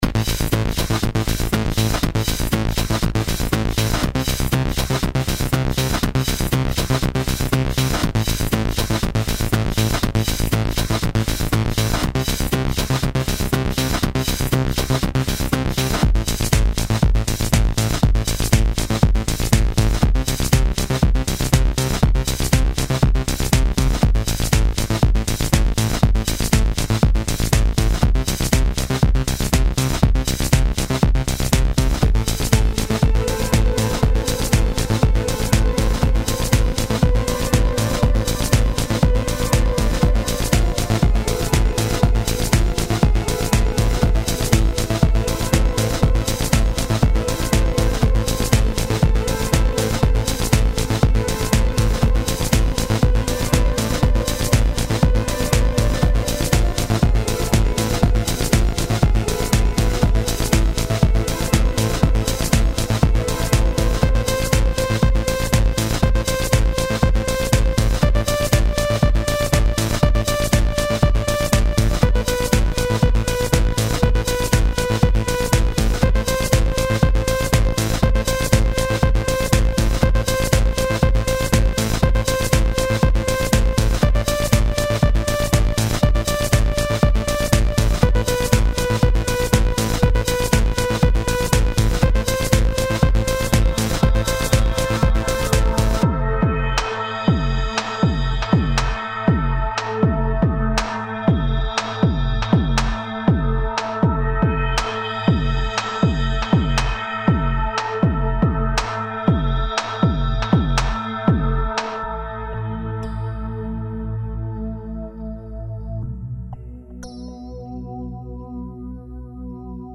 Instrumenal Demo track